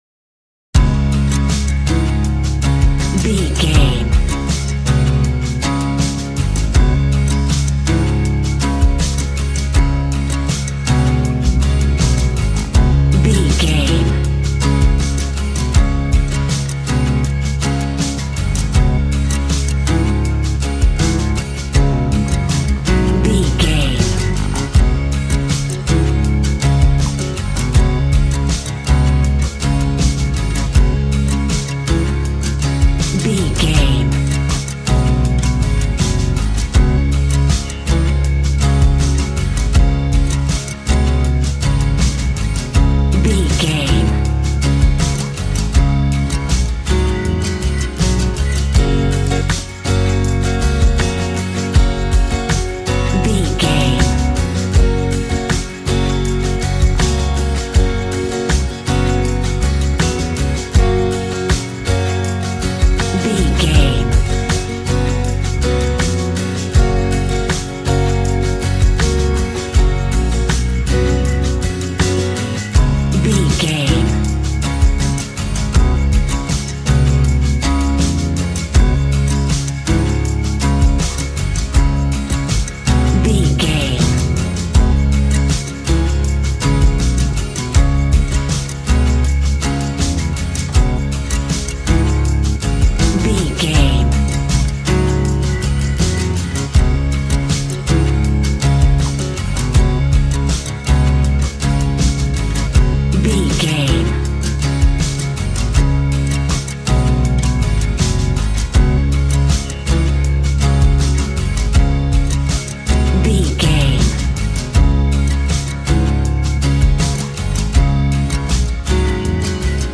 Ionian/Major
D♭
groovy
happy
electric guitar
bass guitar
drums
piano
organ